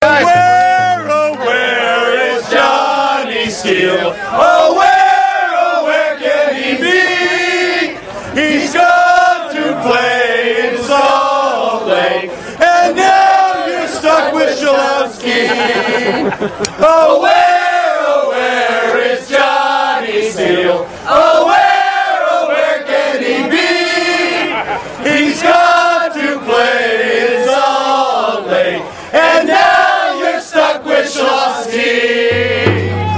Songs and Chants | DARK CLOUDS
Every game, we Dark Clouds sing and chant.
Sometimes, we sing/chant beautifully, in unison. Other times, our lyrics may vary…